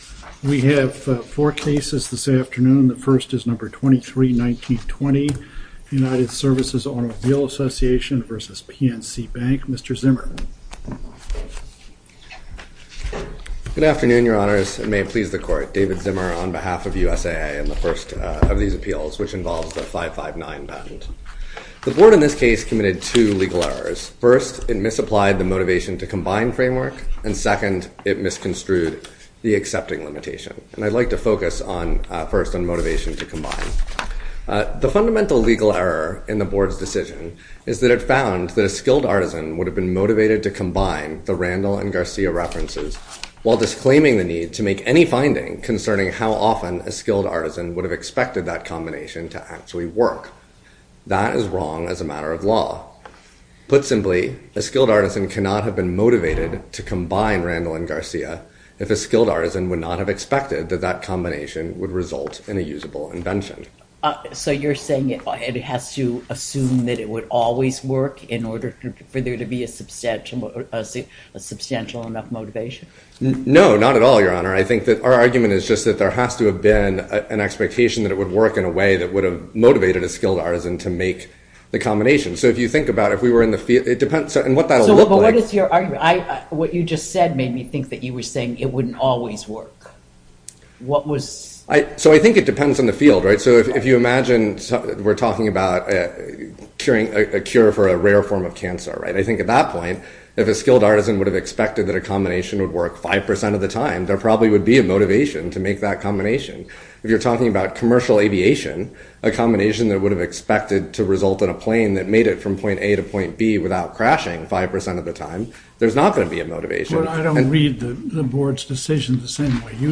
A chronological podcast of oral arguments with improved files and meta data.